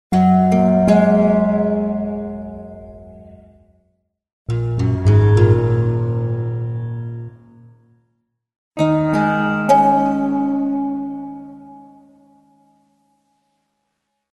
Звуки струн
Игра на струнах японской мелодии